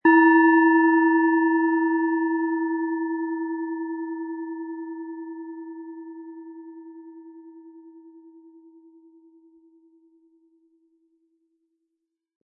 Hopi Herzton
Tibetanische Hopi-Herzton Planetenschale.
Wohltuende Klänge bekommen Sie aus dieser Schale, wenn Sie sie mit dem kostenlosen Klöppel sanft anspielen.